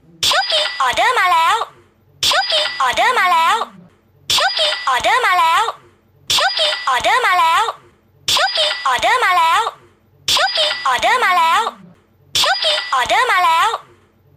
เสียงแจ้งเตือน ออเดอร์มาแล้ว
เสียงเอฟเฟค ออนไลน์ เสียงเรียกเข้า ไรเดอร์ส่งอาหาร
หมวดหมู่: เสียงเรียกเข้า